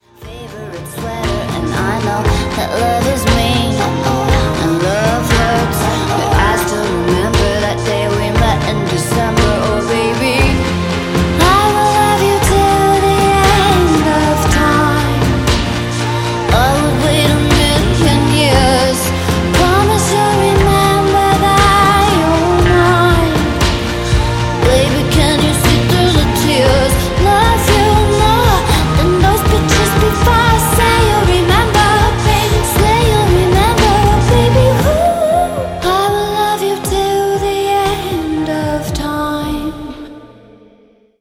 Ho gia detto lasciva? allora dico maliarda.